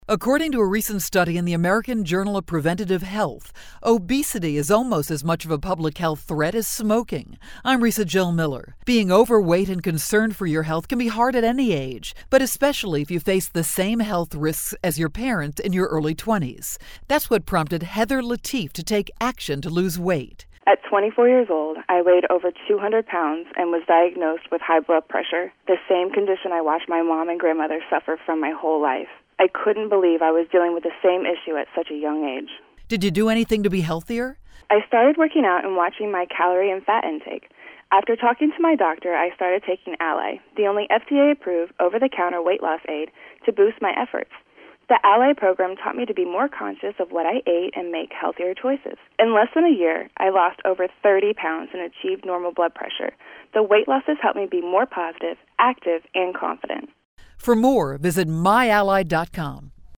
November 30, 2011Posted in: Audio News Release